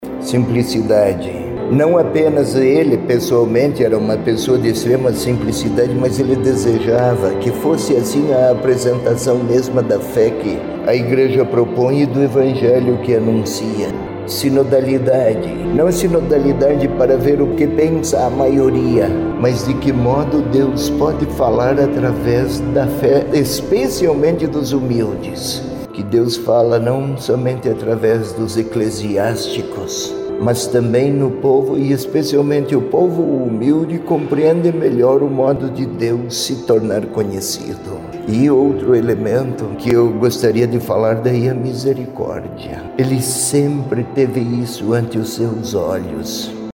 Dom José Peruzzo definiu o legado do Papa em três palavras.